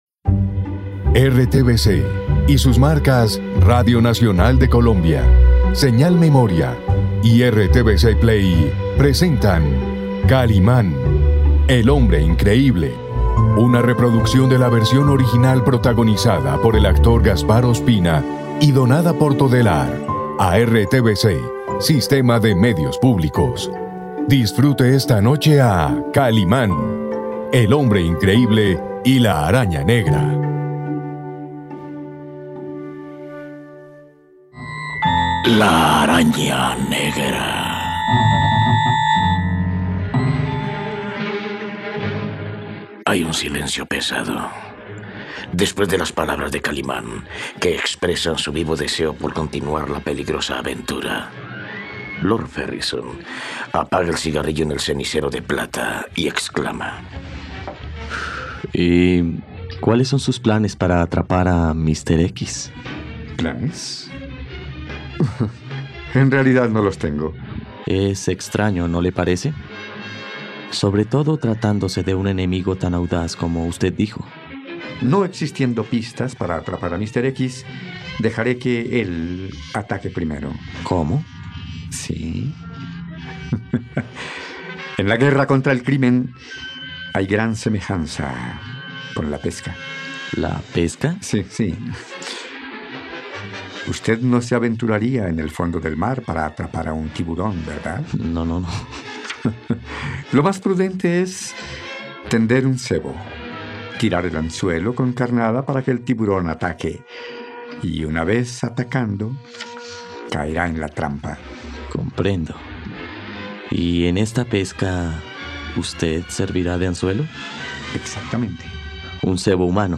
Lord Ferrison le pide a Kalimán que desista de la investigación y salga de Marruecos, pero él insiste en continuar y la lucha es a muerte con Míster x. ¡No te pierdas esta radionovela por RTVCPlay!